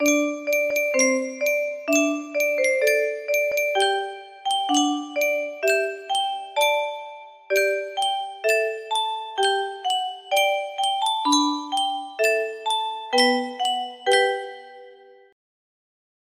Yunsheng Music Box - Project Boite a Musique 2425 music box melody
Full range 60